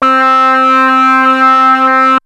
OSCAR OBOE 4.wav